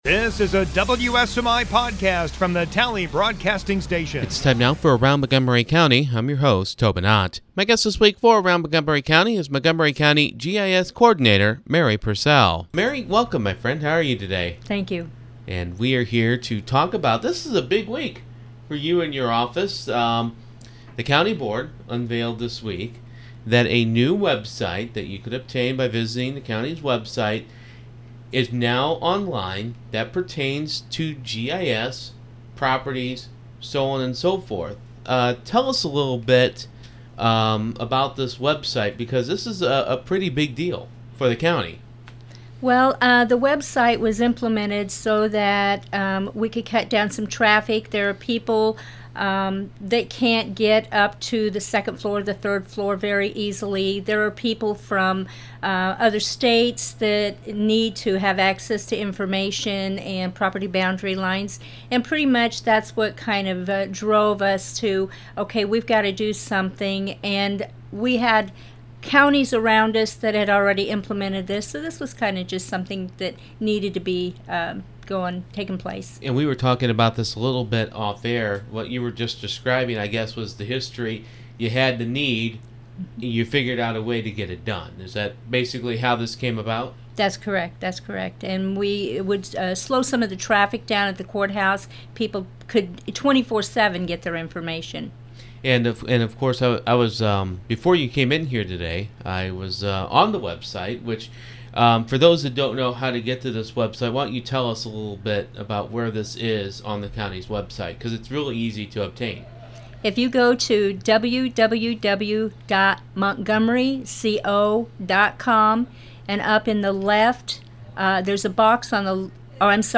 Guest